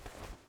Player Character SFX
crouch1.wav